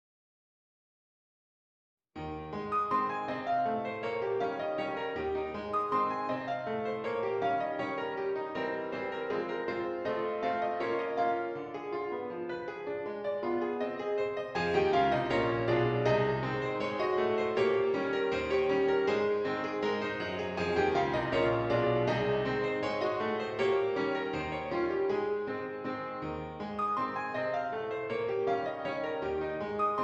Piano Solo
A Flat Major
Slow march tempo